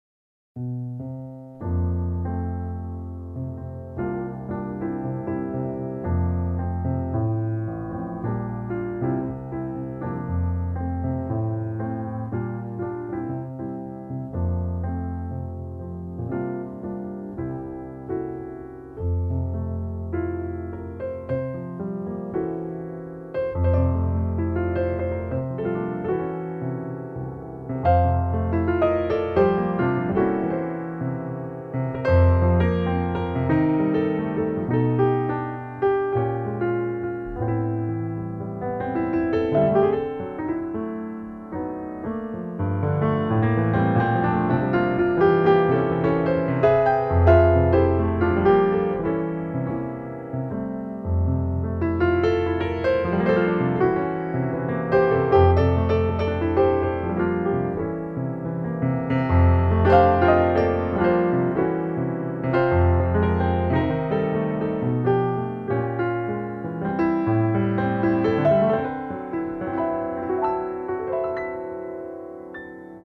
MUSIC FROM LIVE JAZZ FESTIVAL 2002: